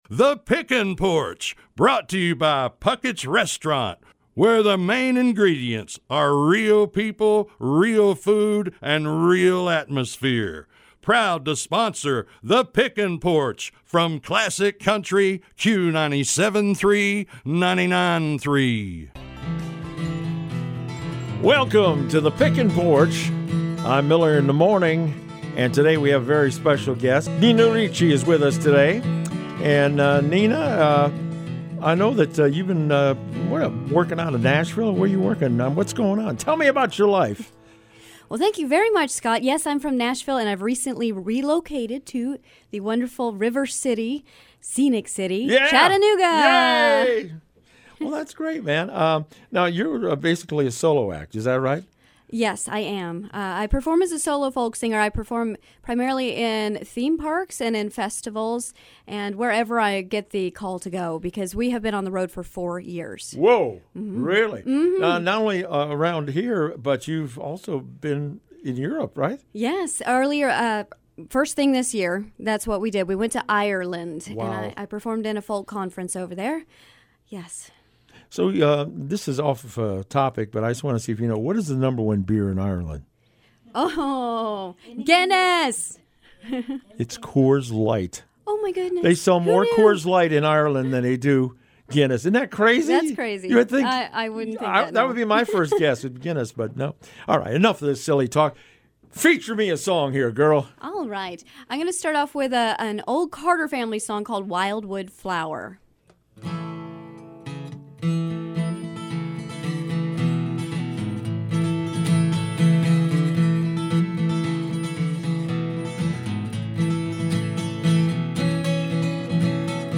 American folk revival
sung with a confident air and savvy lyricism